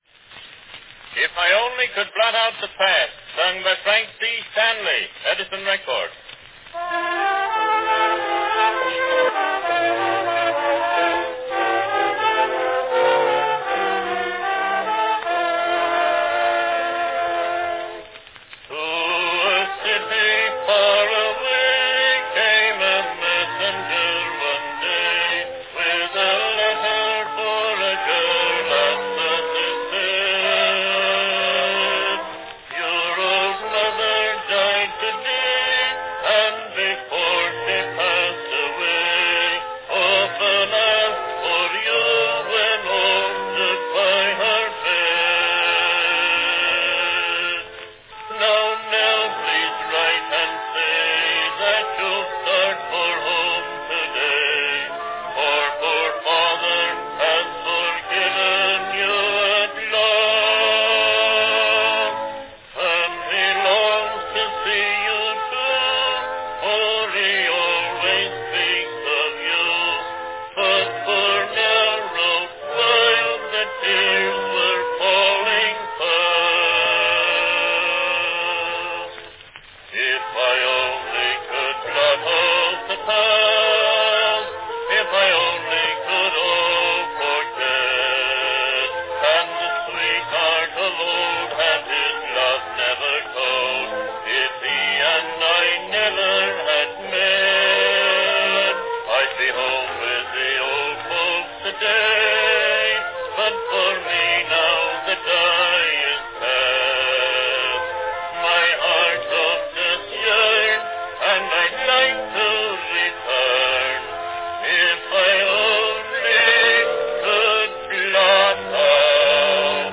Cylinder of the Month
From 1902, a song of homecomings – one too late – If I Only Could Blot Out the Past sung by the popular baritone Frank C. Stanley.
Category Baritone
Performed by Frank C. Stanley
A poignant song with a compelling title.
This recording, announced by Stanley, is a remake of the original brown wax version released by Edison in mid-1899.